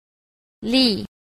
c. 例 – lì- lệ